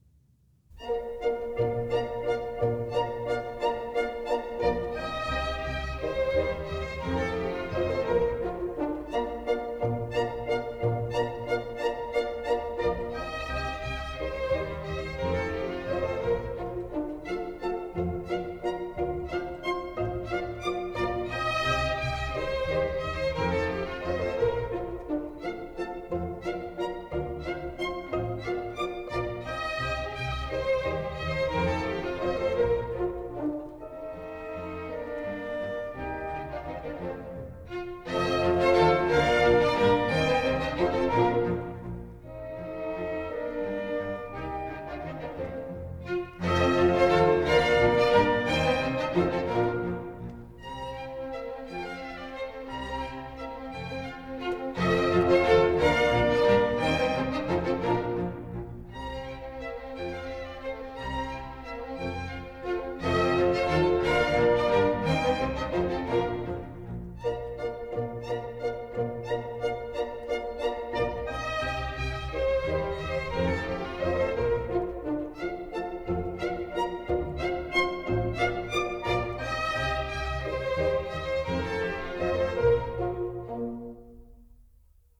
» nhac-khong-loi
Bb